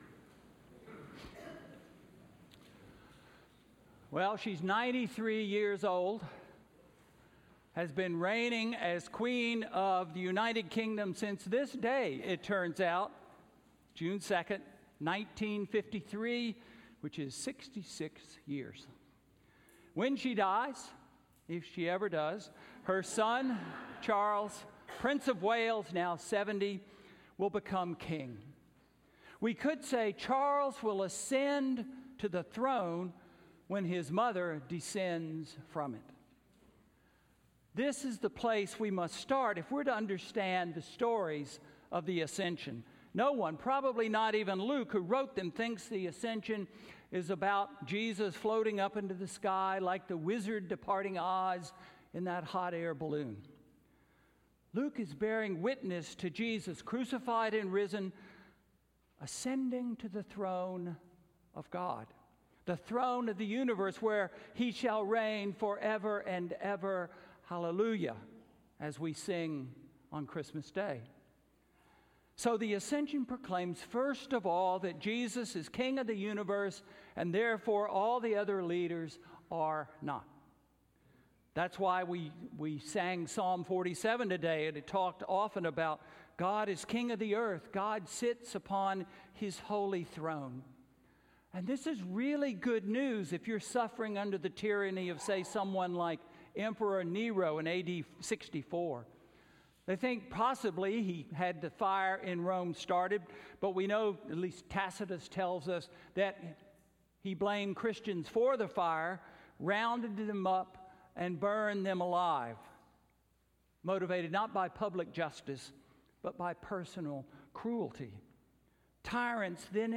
Sermon–Jesus Ascends to the Center?